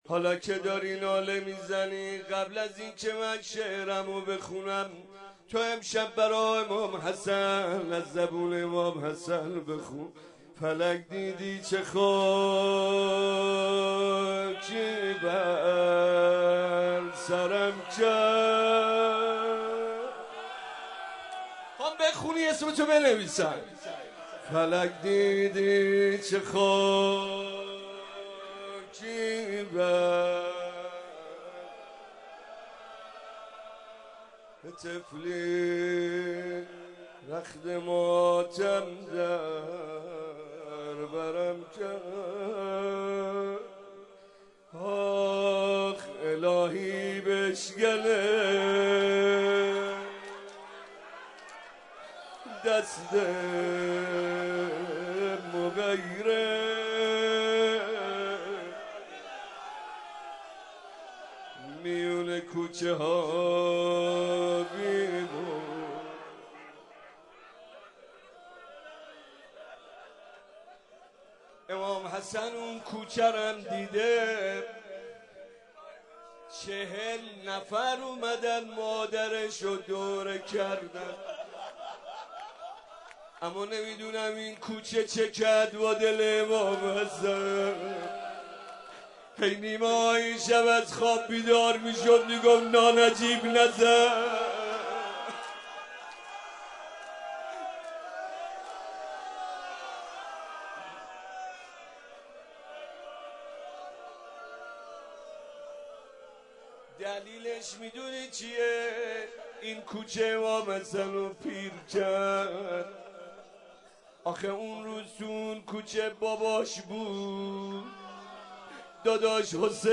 دانلود مداحی فلک دیدی چه خاکی بر سرم کرد - دانلود ریمیکس و آهنگ جدید